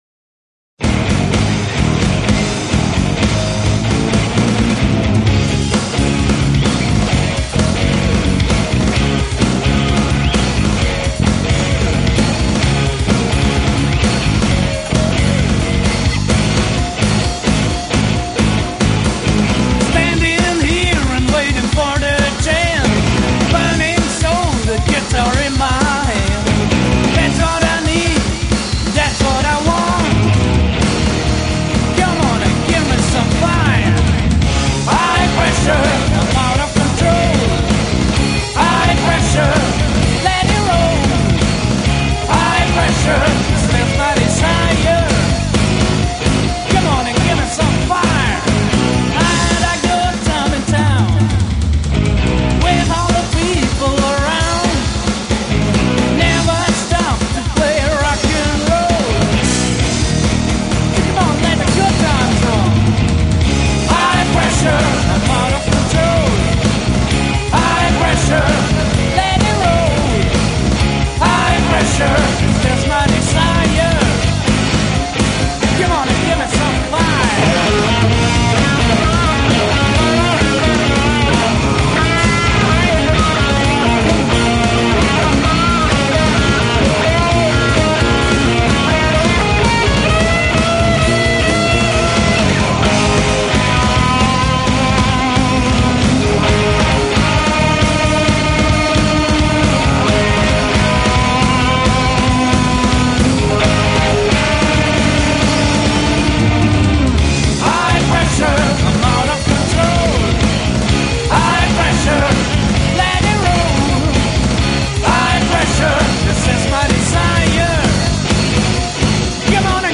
Erste Studioproduktion 1995
alle Vocals & Bass